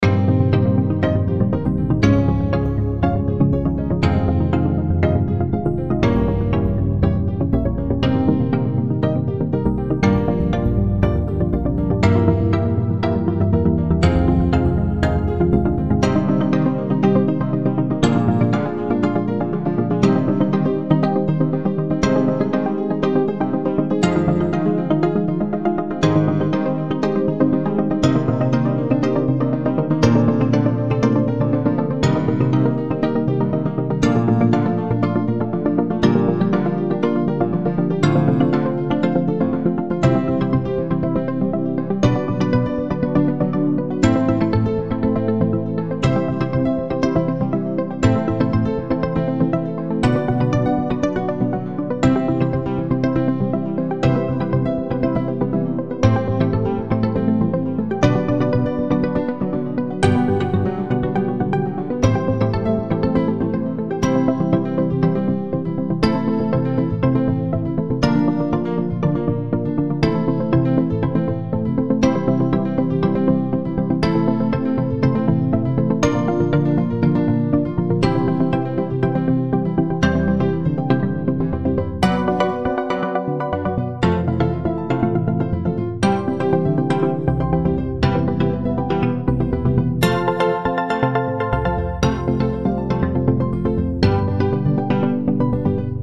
BGM
デジプラック、ピアノ、アコースティックベース